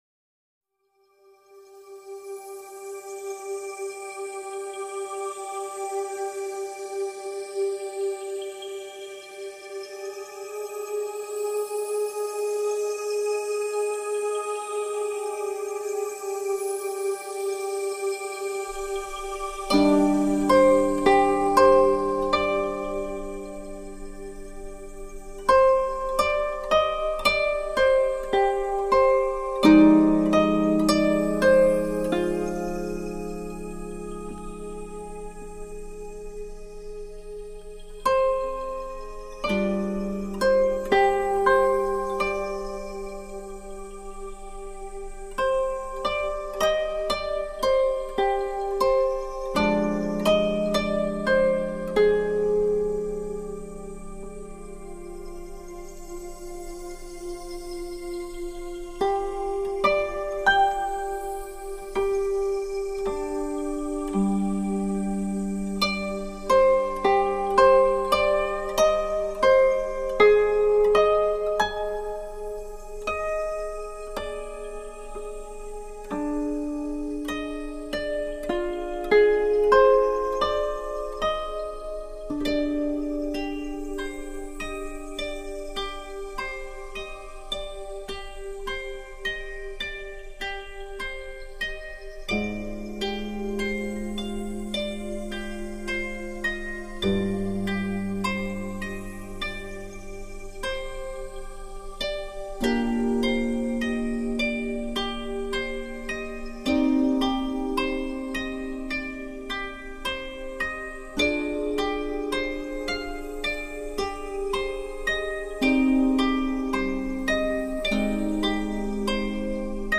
震撼太鼓音色
和太鼓の響きと尺八、箏などの音色が今までにない侍の心を伝える。